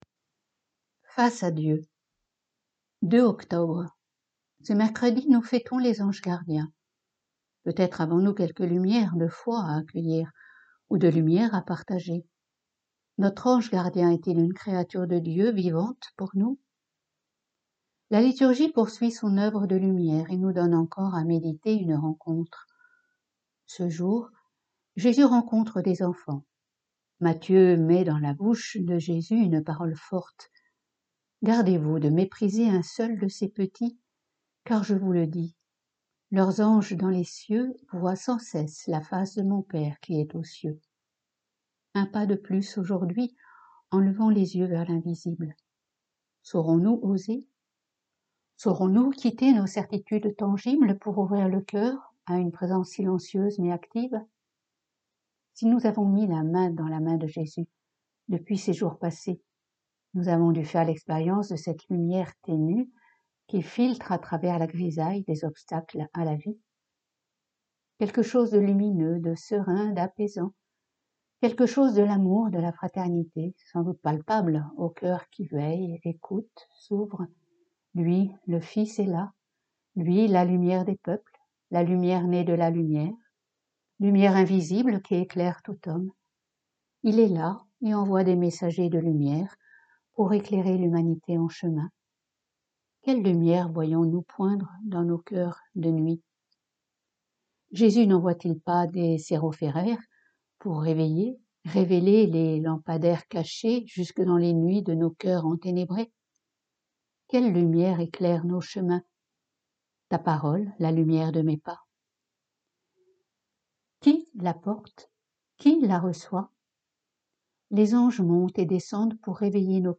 pour chaque billet mentionné ci-dessous, un lien vers le fichier-son enregistré au monastère, suivi d’un lien vers le billet en texte (mise à jour fréquente)